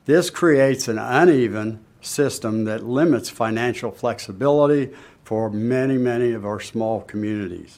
Lewis spoke during an Illinois Municipal League press conference at the Illinois Capitol on Wednesday.